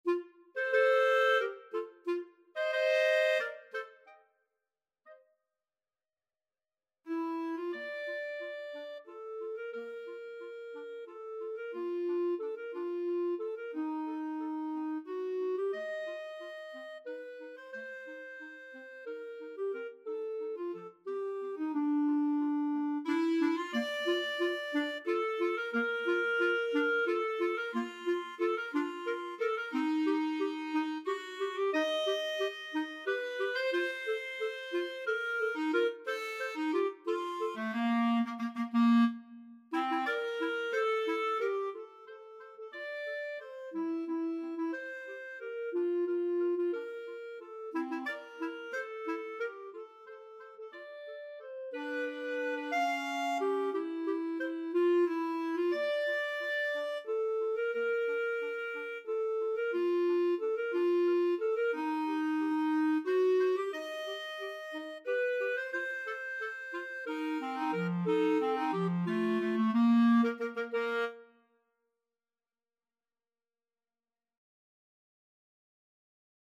Bb major (Sounding Pitch) C major (Clarinet in Bb) (View more Bb major Music for Clarinet Duet )
3/4 (View more 3/4 Music)
~ = 180 Tempo di Valse
Clarinet Duet  (View more Intermediate Clarinet Duet Music)
Classical (View more Classical Clarinet Duet Music)
viennese_blood_waltz_2CL.mp3